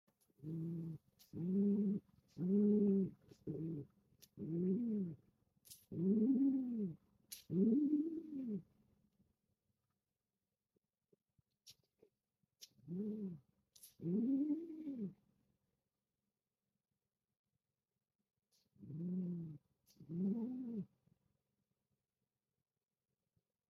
Do you know what a pigeon love hoot sounds like?